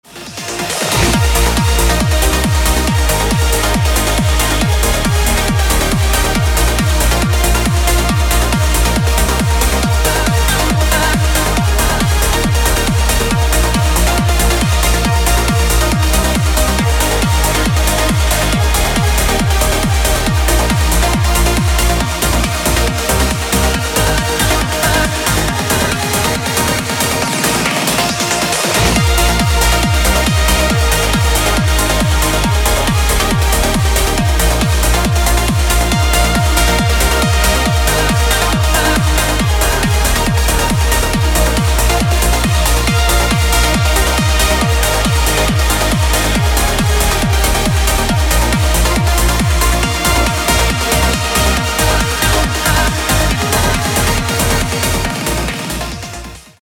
• Качество: 256, Stereo
громкие
dance
Electronic
без слов
Trance